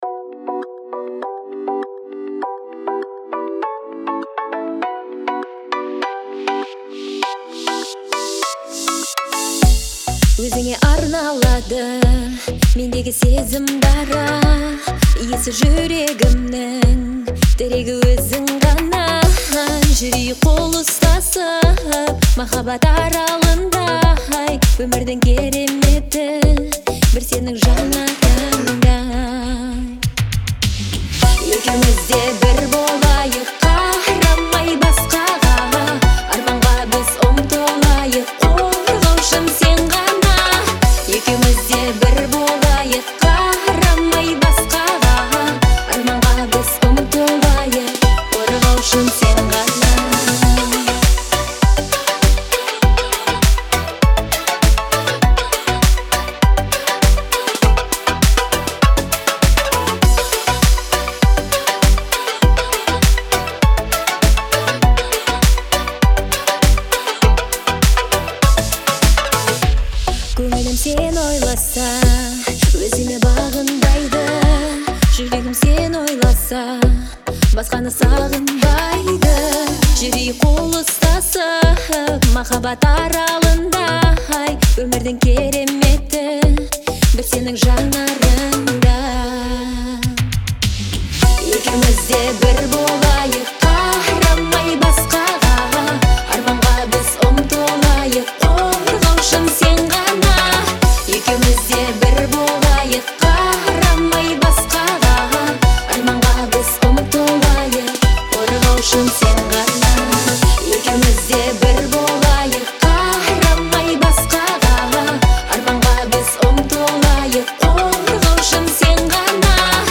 это нежная и трогательная композиция в жанре поп.
отличается мягкими мелодиями и выразительным вокалом